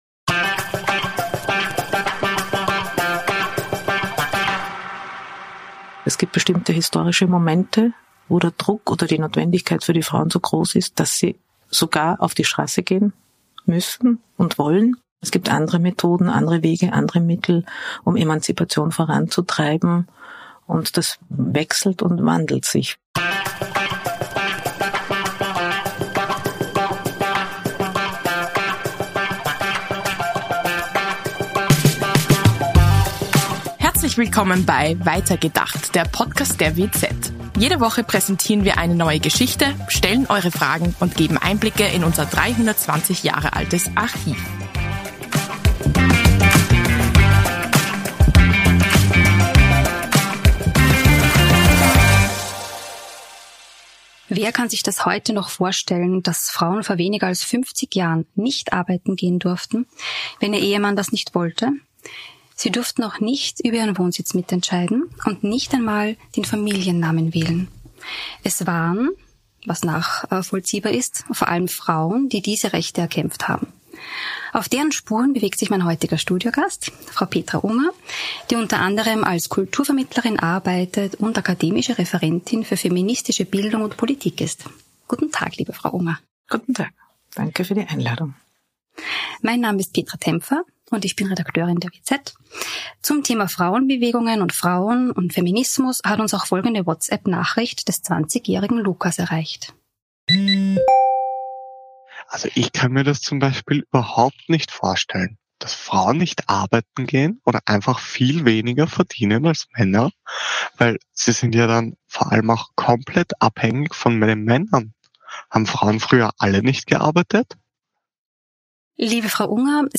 Podcast-Studio-Gast